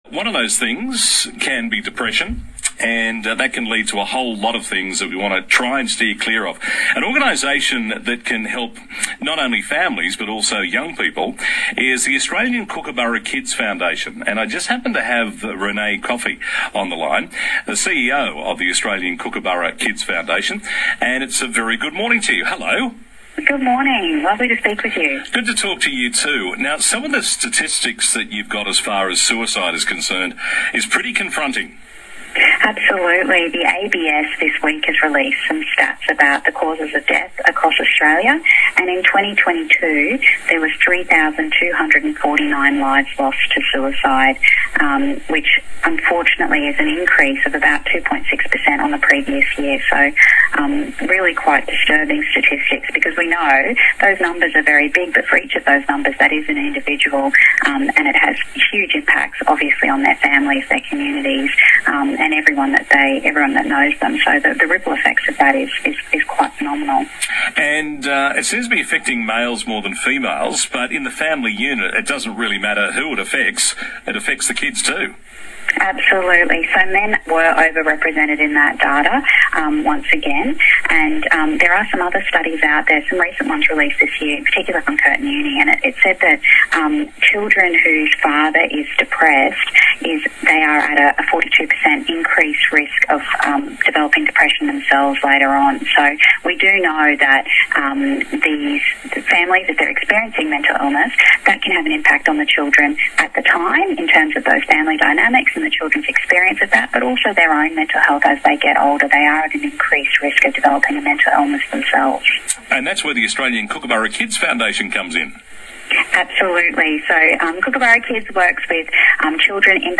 2ST- NSW South Coast – Interview